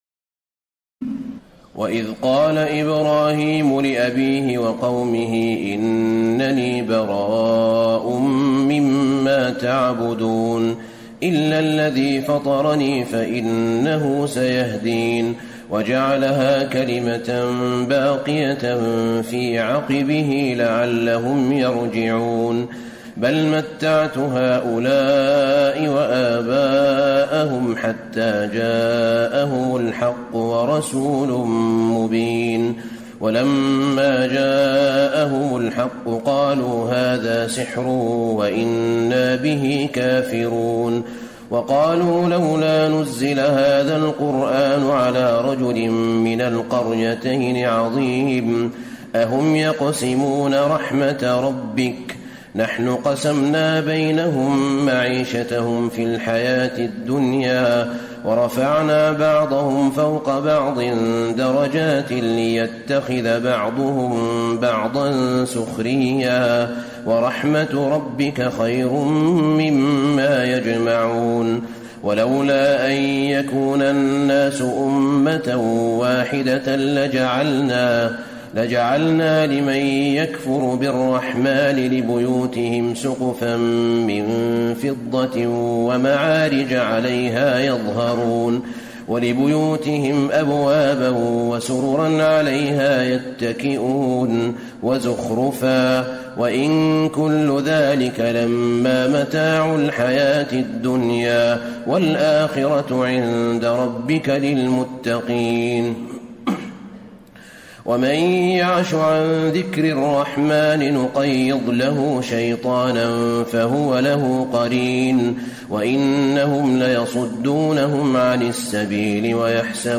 تراويح ليلة 24 رمضان 1434هـ من سور الزخرف (26-89) والدخان و الجاثية Taraweeh 24 st night Ramadan 1434H from Surah Az-Zukhruf and Ad-Dukhaan and Al-Jaathiya > تراويح الحرم النبوي عام 1434 🕌 > التراويح - تلاوات الحرمين